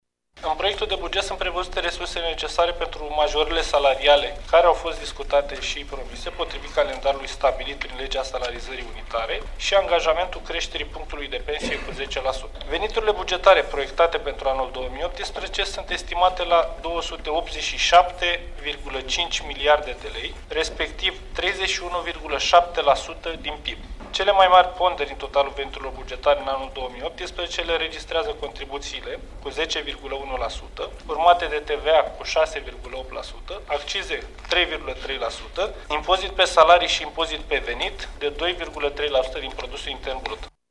Ministrul de finanţe, Ionuţ Mişa, susţine că priorităţile Executivului pentru 2018 sunt sănătatea, educaţia şi investiţiile :